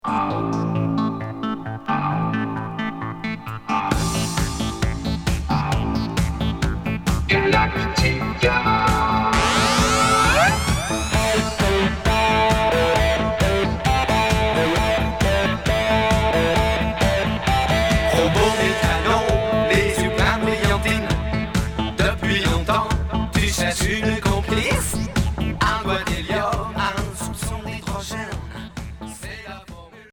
New wave cosmique